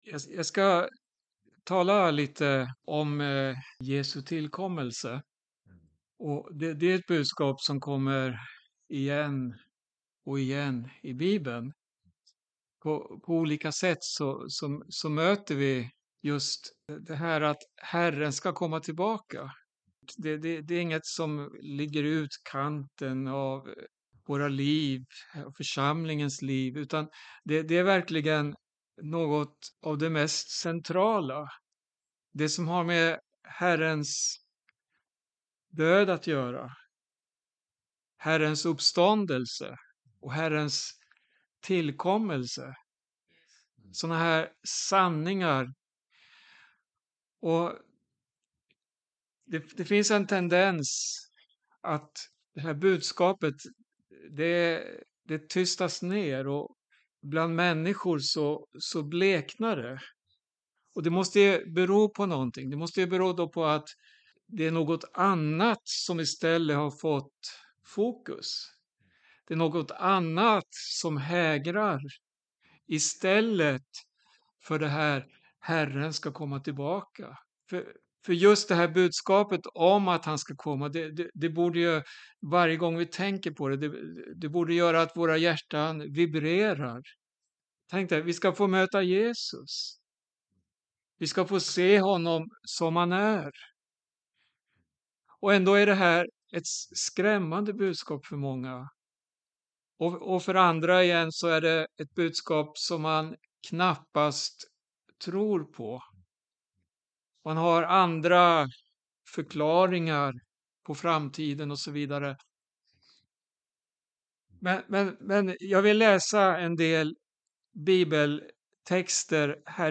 Bibelstudium om Jesu tillkommelse.